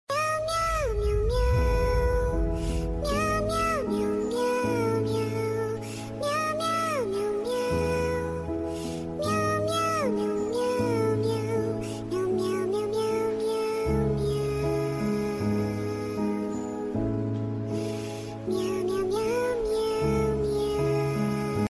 ASMR singing cat video ASMR sound effects free download
ASMR singing cat video ASMR satisfying sound